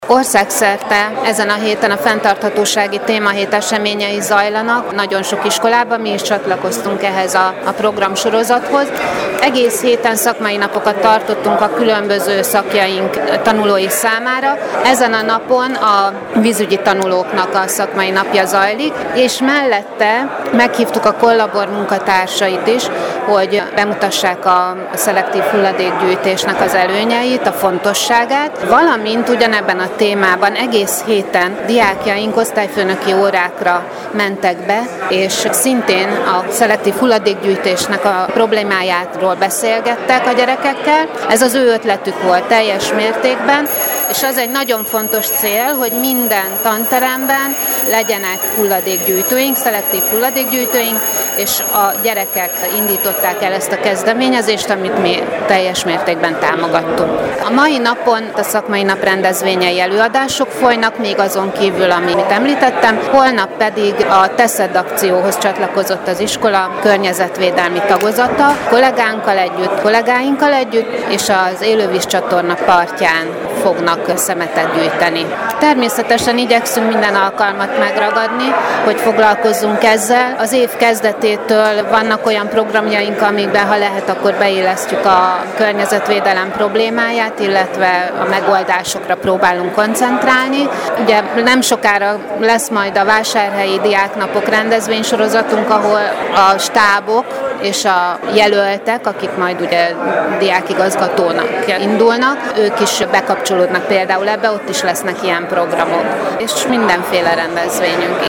interjút